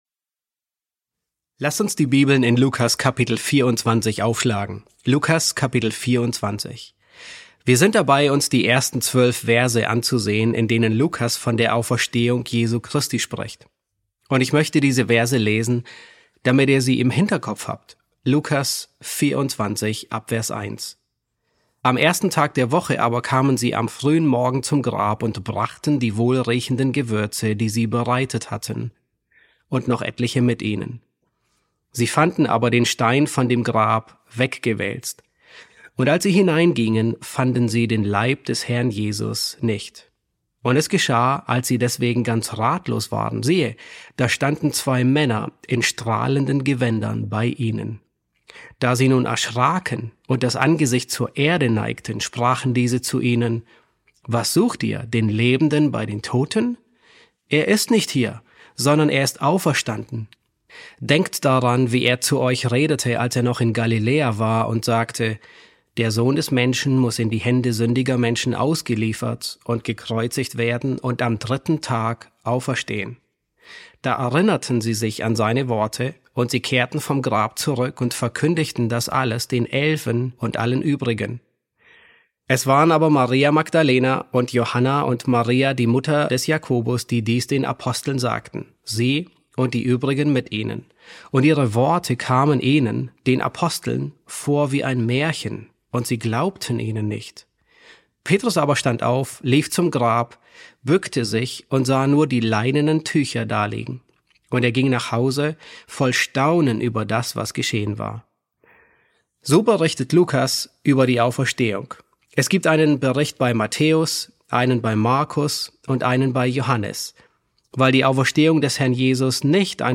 E3 S2 | Frauen bezeugen und Jünger bezweifeln ~ John MacArthur Predigten auf Deutsch Podcast